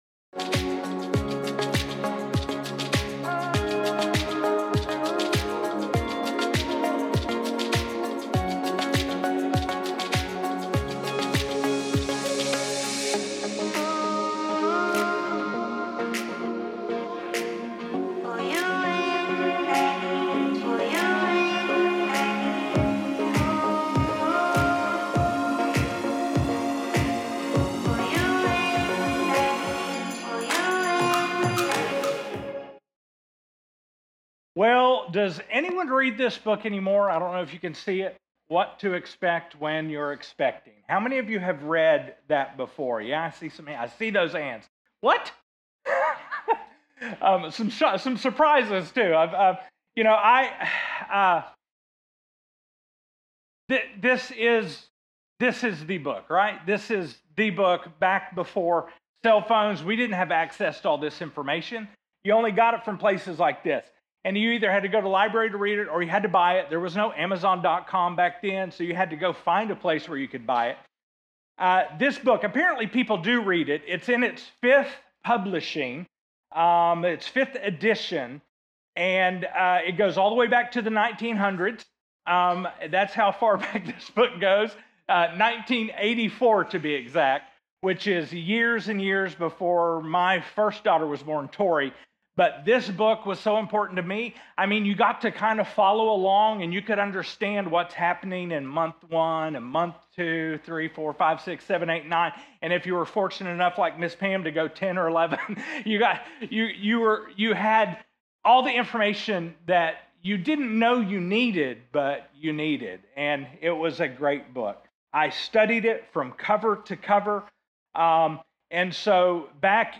Download Download From this series Current Sermon 8 of 8 JESUS 2.0 READY...